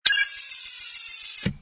hud_zoom_out.wav